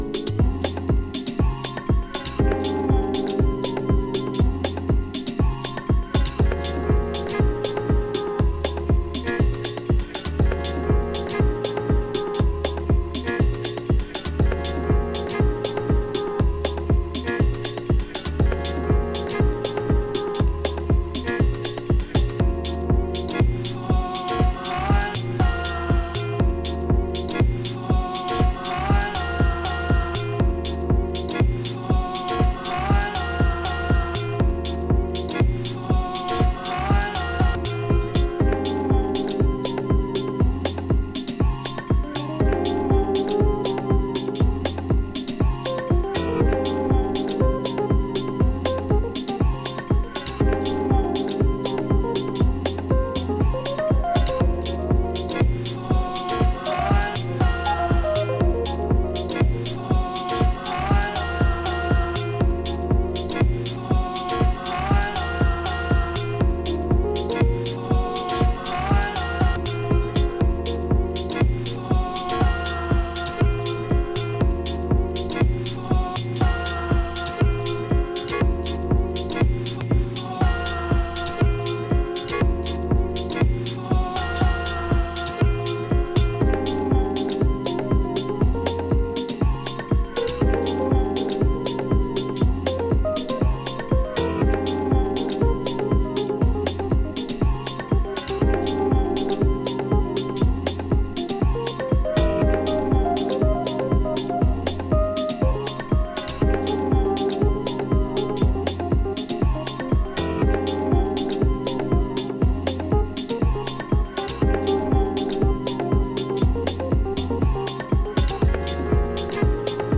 頭をもたげるようなレイトナイト感覚が充満した、彼らしい個性が光る1枚です！